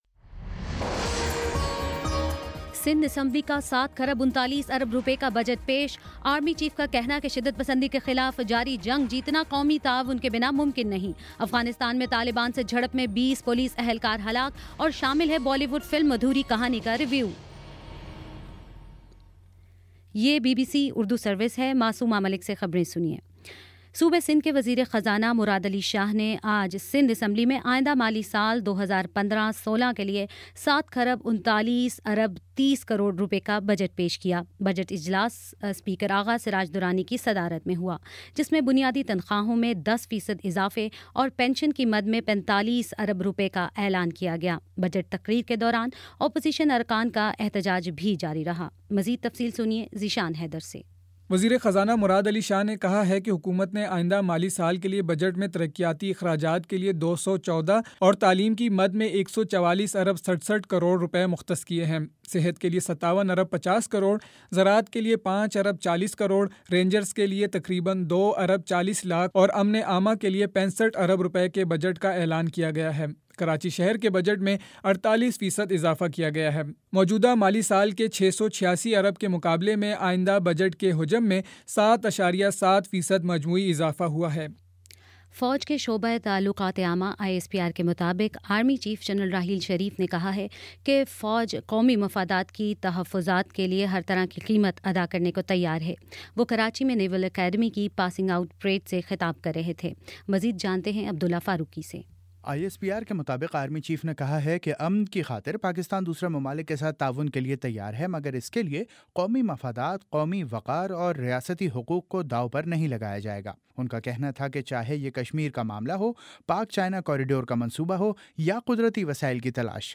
جون 13: شام پانچ بجے کا نیوز بُلیٹن